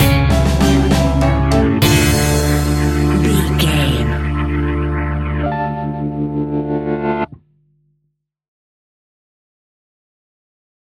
Aeolian/Minor
ominous
eerie
electric guitar
violin
piano
strings
bass guitar
drums
percussion
horror music